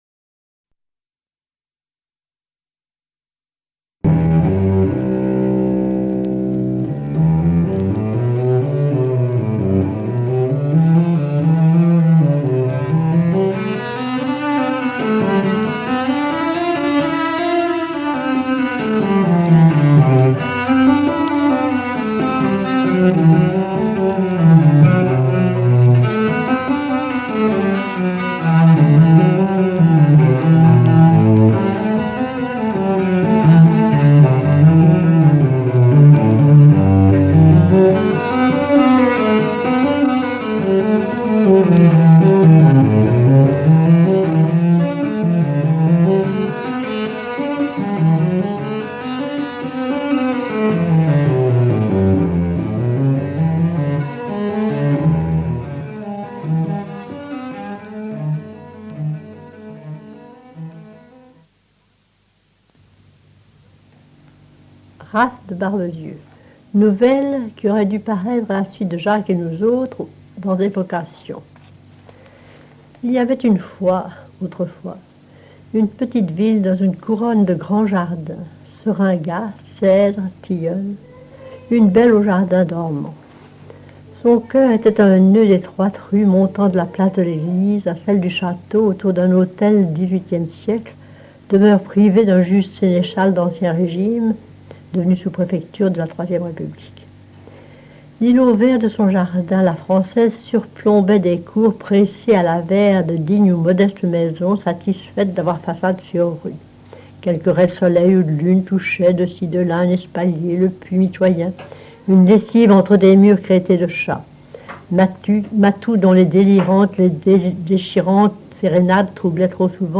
Lecture d'une de ses nouvelles par Geneviève Fauconnier
Sonore - Nouvelle lue par Genevieve Fauconnier - 60xxxx.mp3